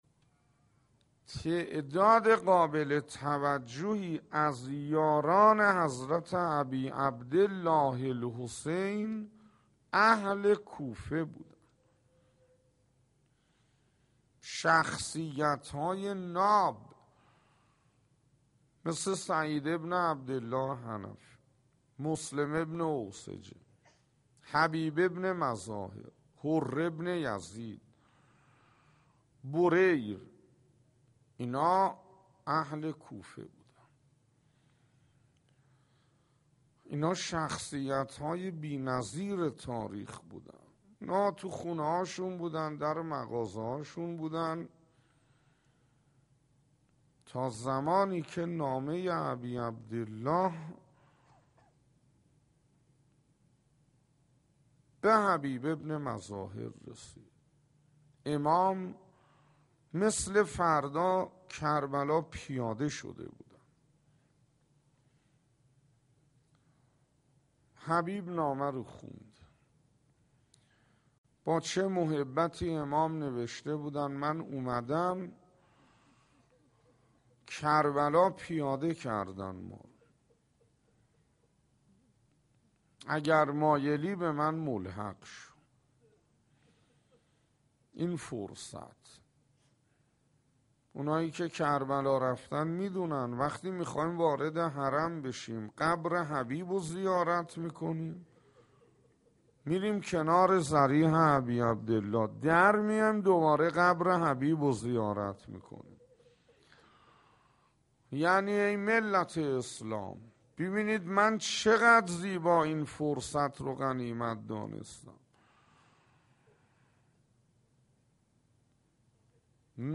روضه یاران
روضه یاران خطیب: استاد حسين انصاريان مدت زمان: 00:06:06